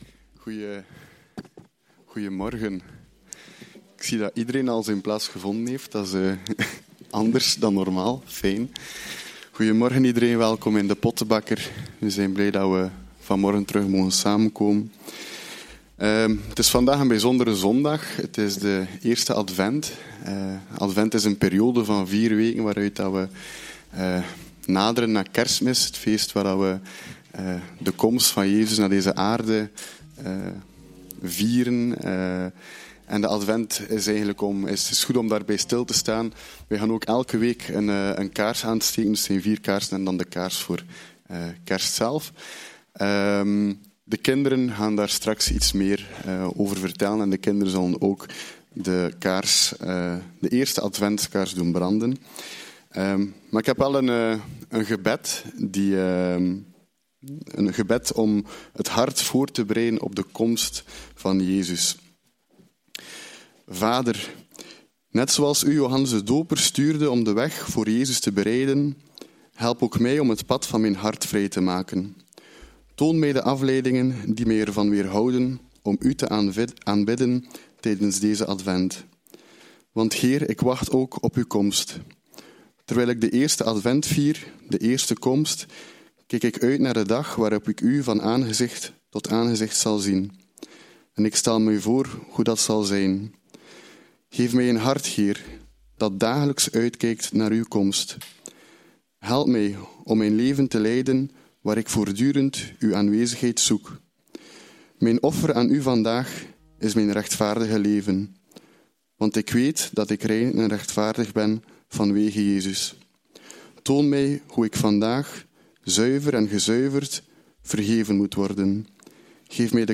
Aantekeningen bij de preek III.